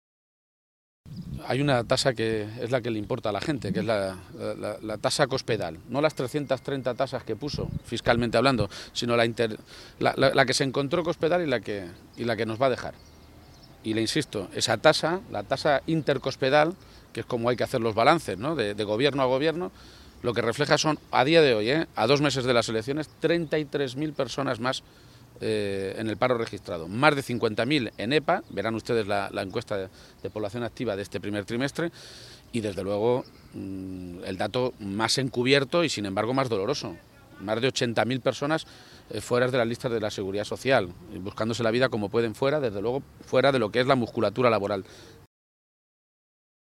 García-Page se pronunciaba de esta manera esta mañana, en Toledo, a preguntas de los medios de comunicación el día que se han conocido los datos del paro registrado durante el pasado mes de febrero, con los que ha lamentado que Cospedal “lamentablemente, se sale hasta de la horma. En el conjunto de España, el paro baja. En Castilla-La Mancha, el paro sube”.
Cortes de audio de la rueda de prensa